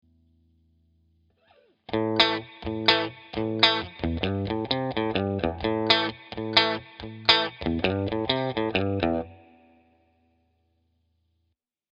In the above example we’ve added a slide on the second and sixth note in the second bar.